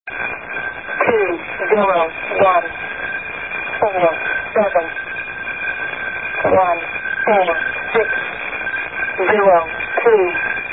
The results of poking around HF on a Saturday night.
Frequency: 4638 kHz. Plain old AM, as far as I could tell.
The transmission continued like this until 0341 UTC, whereupon it stopped with the curt anouncement "End".
numbers.mp3